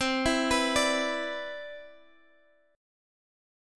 CM9 chord